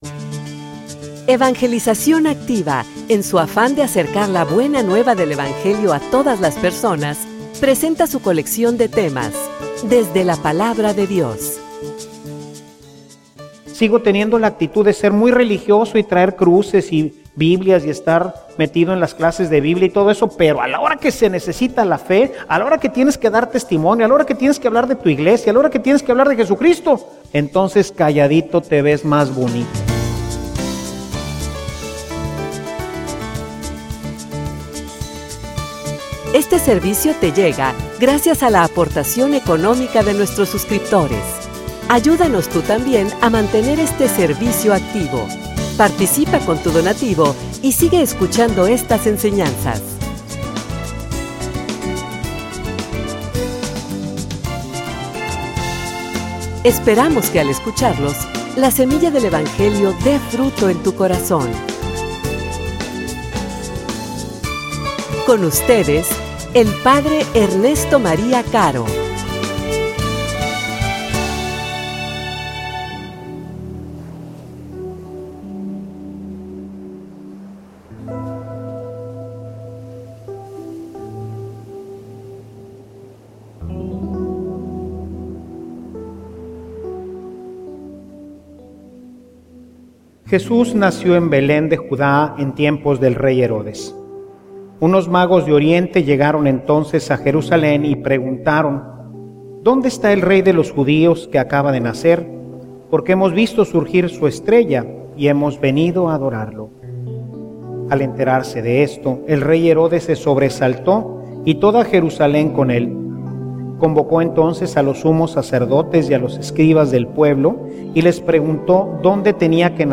homilia_Buscalo_tu_tambien.mp3